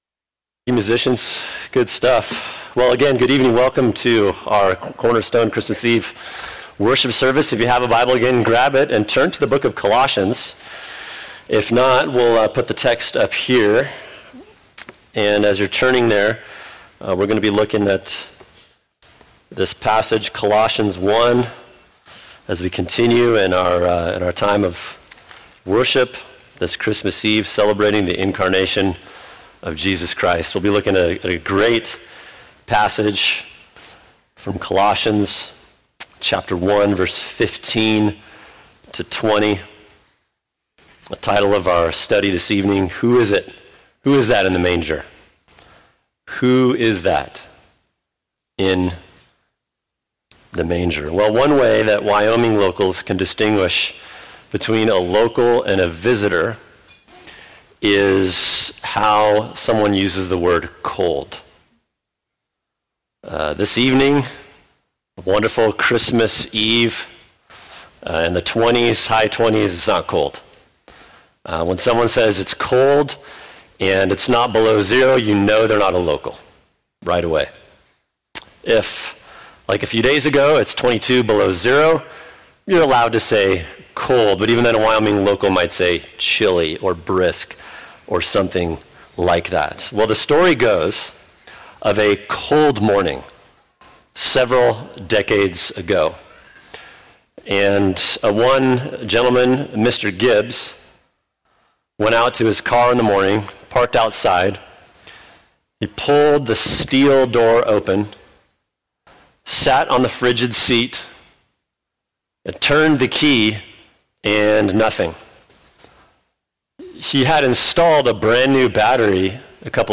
[sermon] Colossians 1:15-20 Who Is That In The Manger?